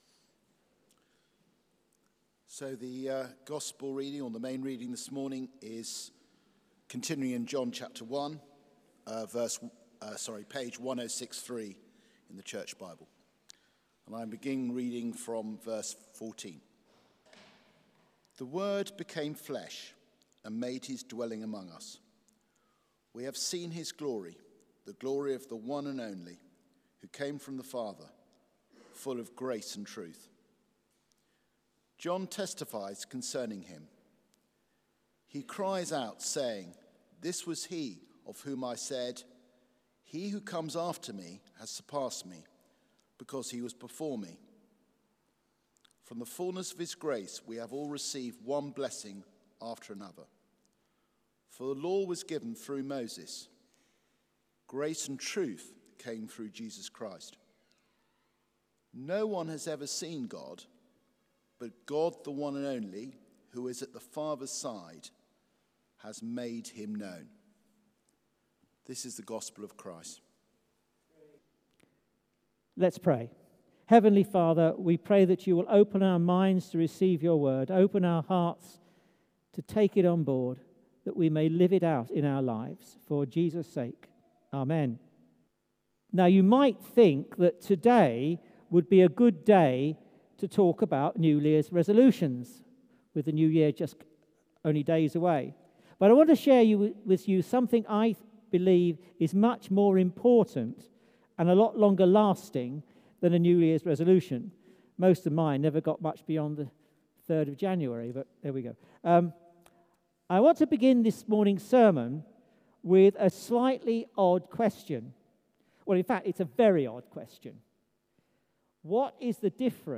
Christmas with John: Light Has Come Theme: The Visible God Sermon